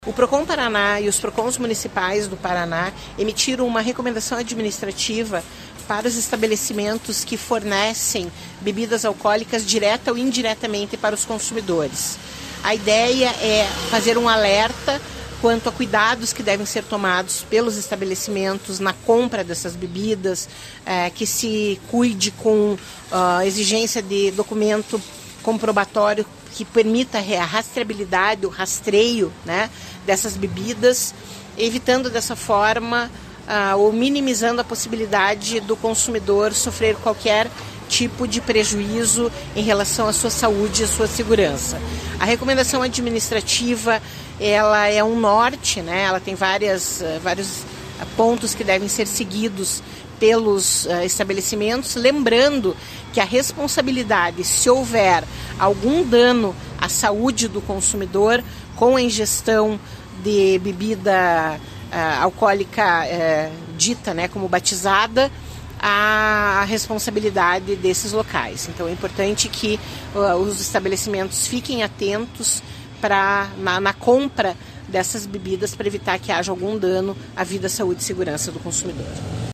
Sonora da coordenadora do Procon-PR, Claudia Silvano, sobre o alerta para comércios de bebidas sobre caso metanol